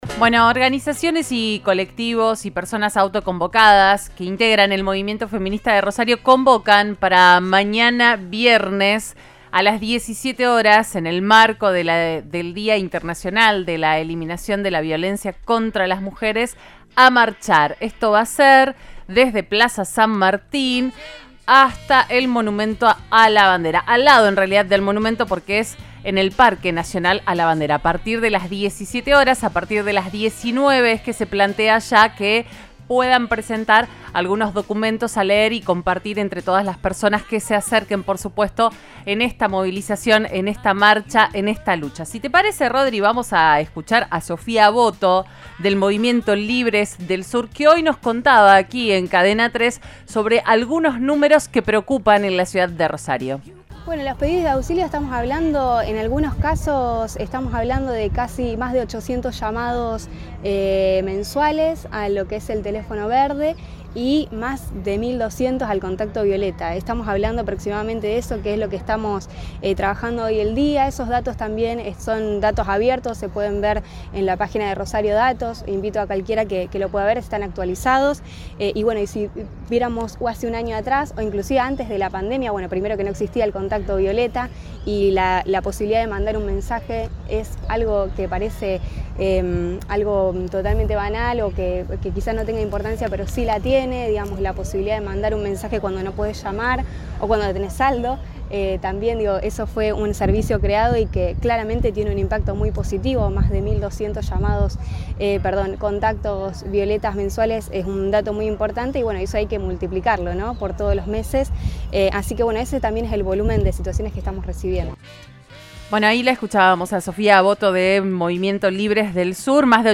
Florencia Marinaro habló en Cadena 3 Rosario tras la difusión de cifras en la provincia y el país. Se refirió a las diferencias entre las cifras de su cartera y las elaboradas por otros observatorios.